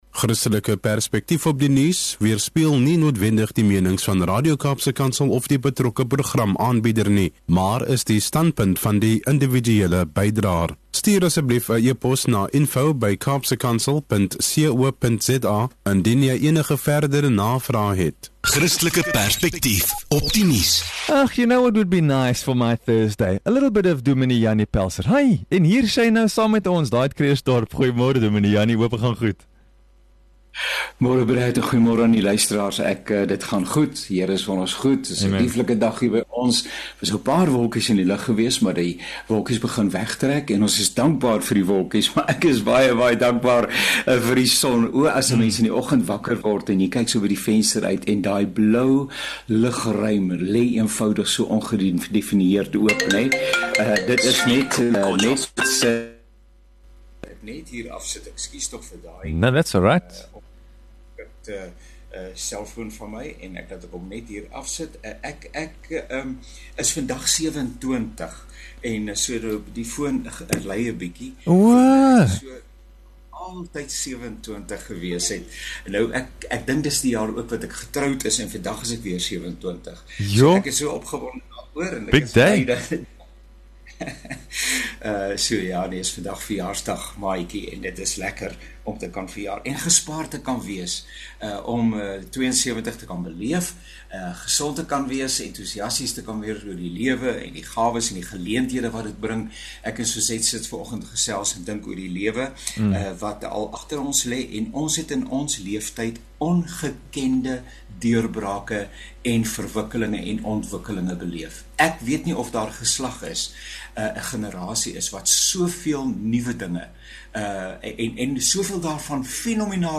Met ’n ligte, geselsende aanslag en ’n fokus op geloof, hoop en positiewe denke, herinner hierdie podcast ons om dankbaar te wees vir elke nuwe dag en vir God se seëninge, groot én klein.